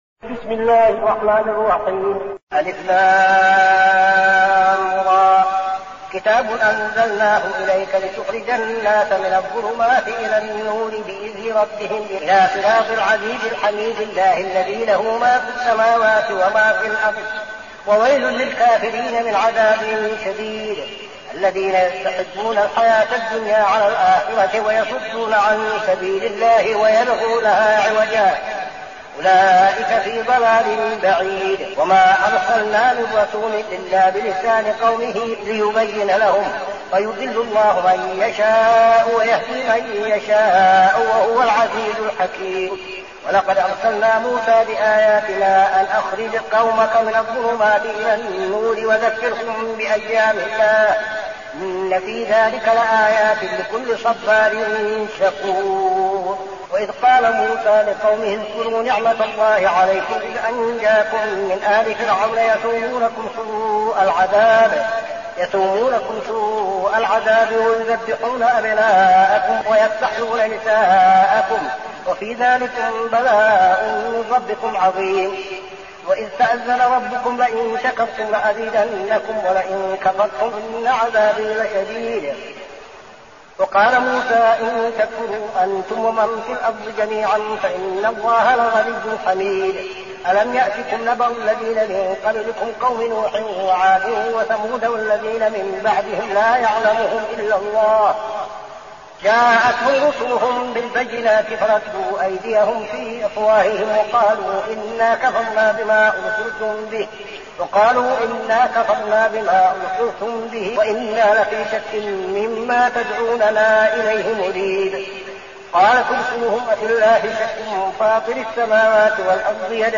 المكان: المسجد النبوي الشيخ: فضيلة الشيخ عبدالعزيز بن صالح فضيلة الشيخ عبدالعزيز بن صالح إبراهيم